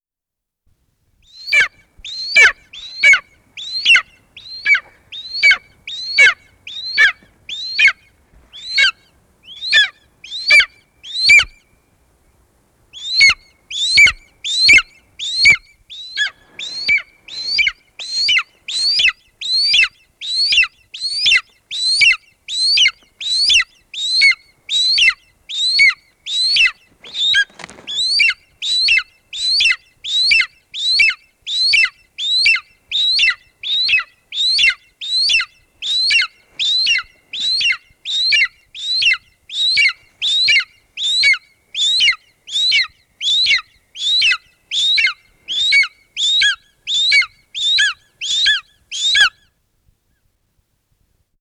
Steinadler Ruf
Steinadler-Ruf-Voegel-in-Europa.wav